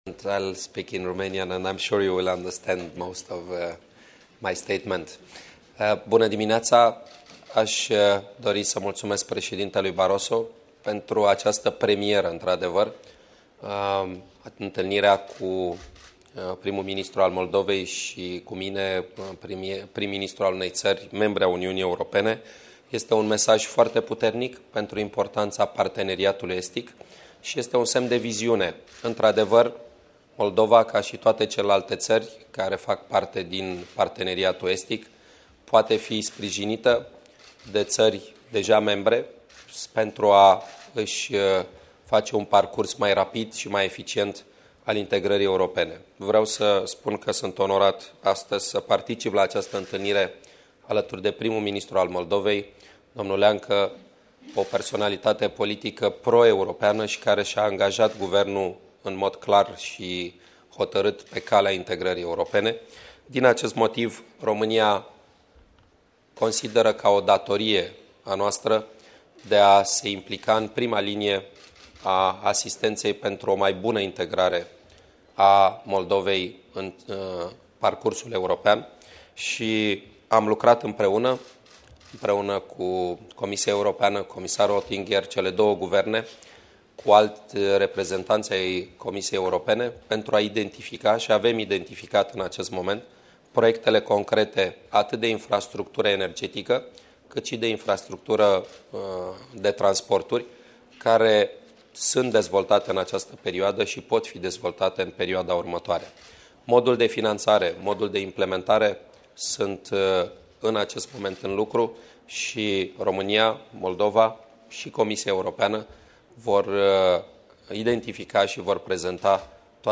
Declarația primului ministru Victor Ponta la conferința de presă de la Bruxelles